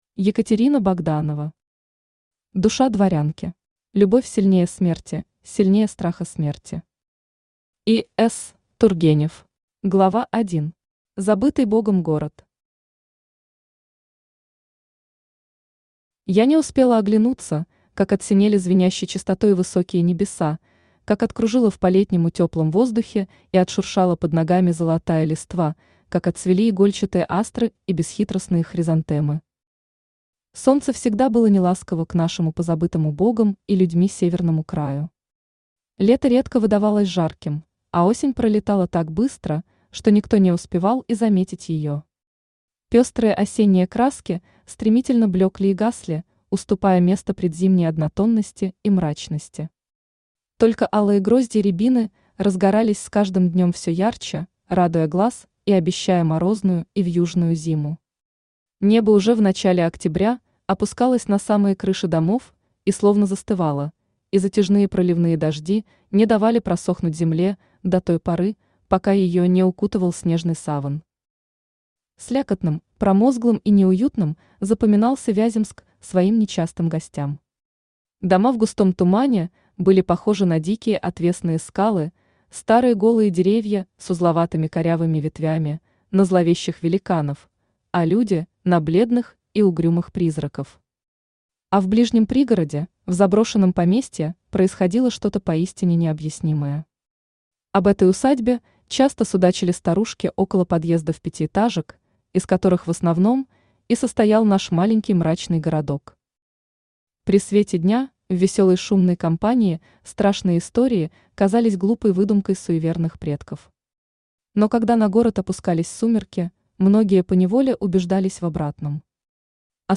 Аудиокнига Душа дворянки | Библиотека аудиокниг
Aудиокнига Душа дворянки Автор Екатерина Андреевна Богданова Читает аудиокнигу Авточтец ЛитРес.